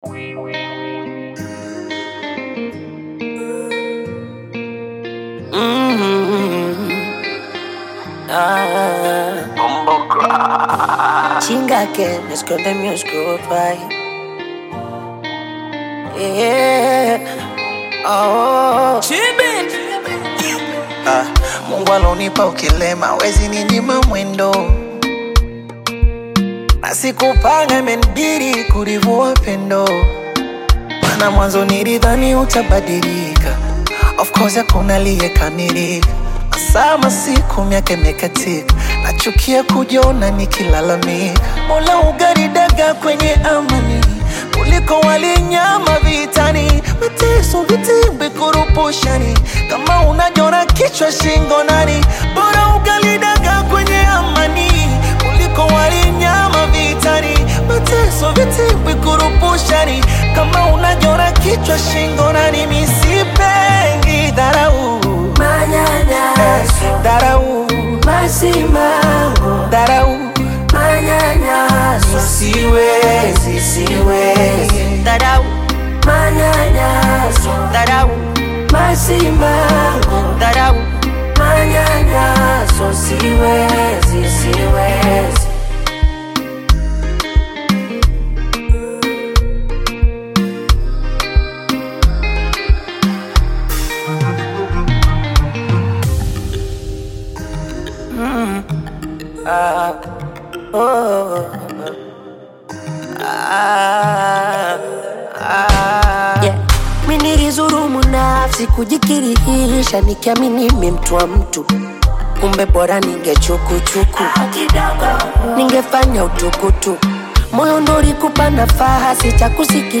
emotional Bongo Flava collaboration
Genre: Bongo Flava